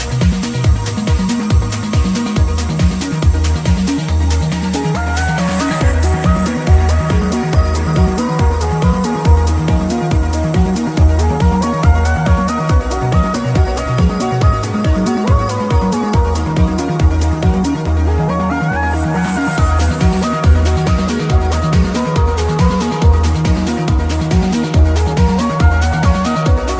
hey this track is just amazing. very chill check it out
Very chill.